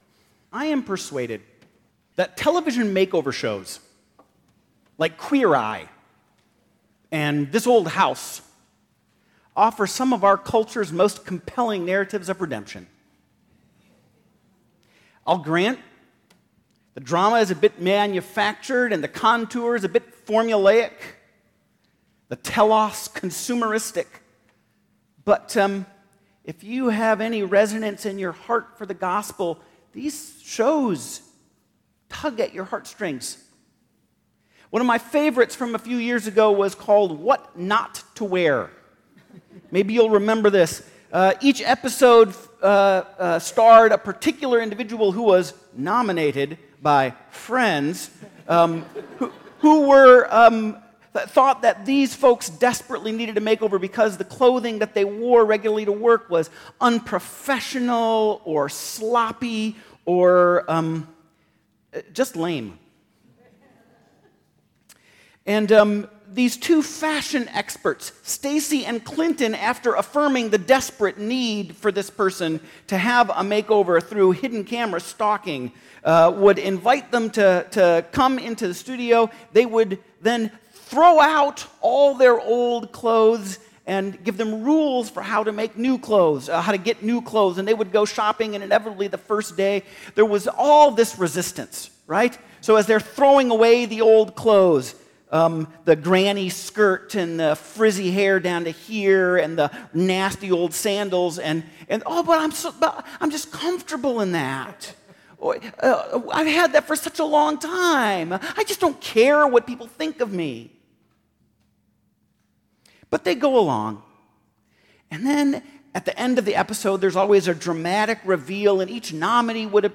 2018 Sermons Do You Want to be Made Well?